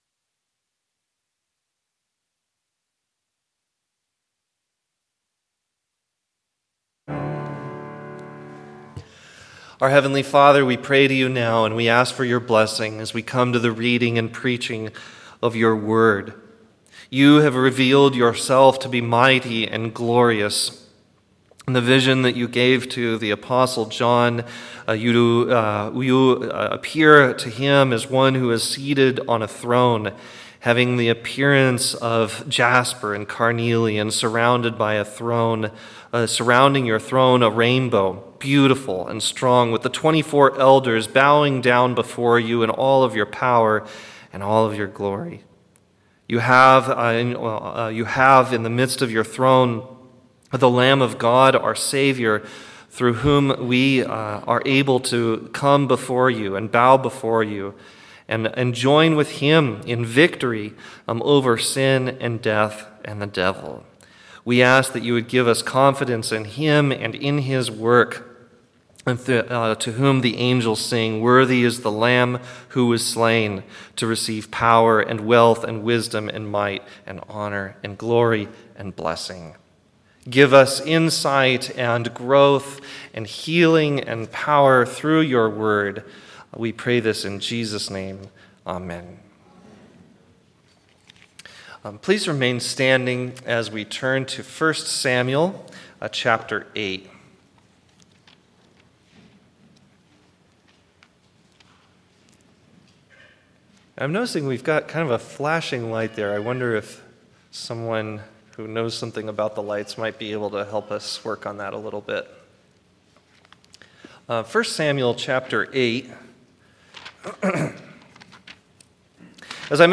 1 Samuel 8:1-22 &nbsp; Pastor